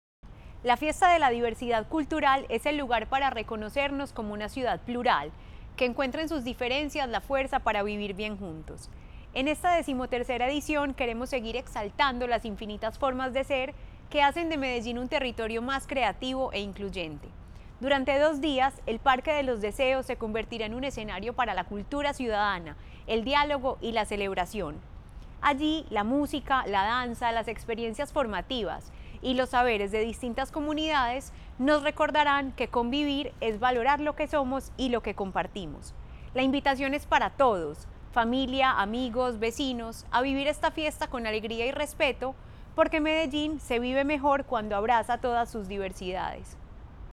Declaraciones subsecretaria de Ciudadanía Cultural, Natalia Londoño